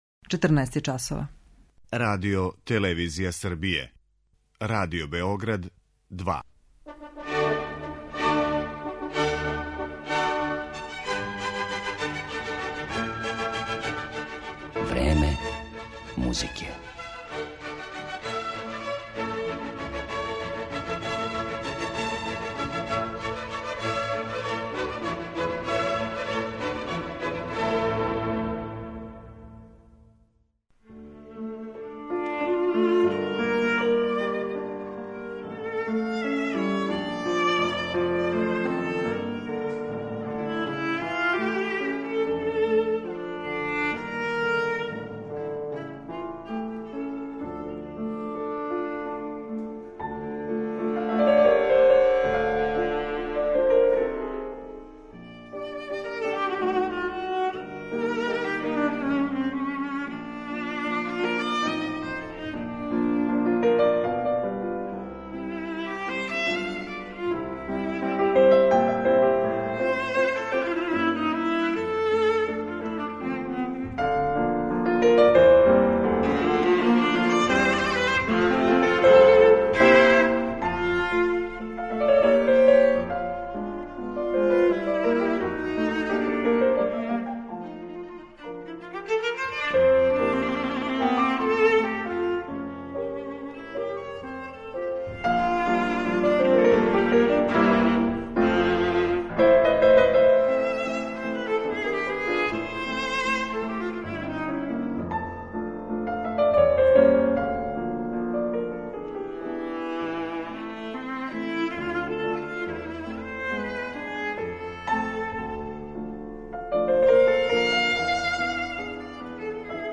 Емисија је посвећена виолисткињи Табеи Цимерман
Једном од највећих виртуоза на виоли данашњице, Табеи Цимерман, посвећена је данашња емисија Време музике, у којој ће славна немачка солисткиња изводити композиције Роберта Шумана, Ђерђа Лигетија, Јоханеса Брамса и Фрица Крајслера.